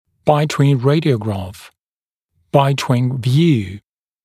[‘baɪtwɪŋ ‘reɪdɪəugrɑːf] [‘baɪtwɪŋ vjuː][‘байтуин ‘рэйдиогра:ф] [‘байтуин вйу:]прикусная рентгенограмма сегментов боковых групп зубов для визуализации коронок, высоты альвеолярной кости, локализации эмалево-дентинной границы, межзубного и рецидивирующего кариеса и пр.